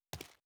01_硬地面_1.wav